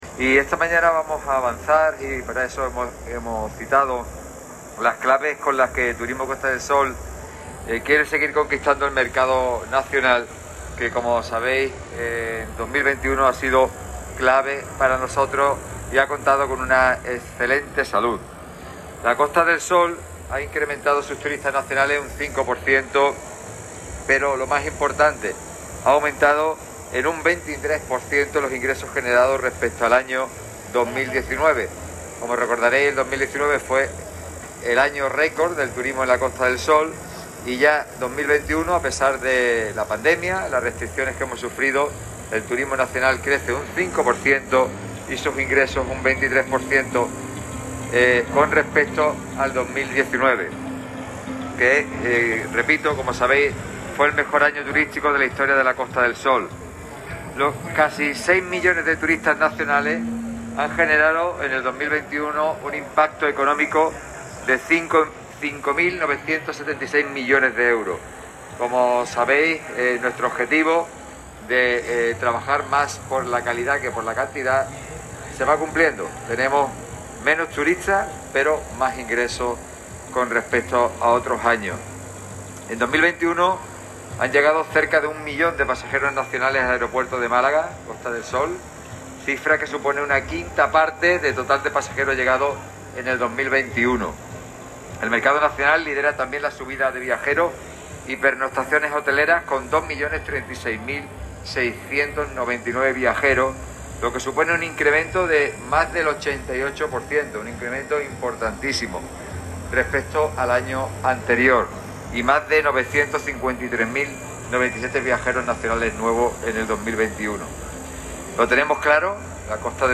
Así lo ha expuesto el presidente de la Diputación y de Turismo Costa del Sol, Francisco Salado, en una rueda de prensa en la que ha afirmado que “los casi seis millones de turistas nacionales han generado en el año 2021 un impacto económico de 5.976 millones de euros”.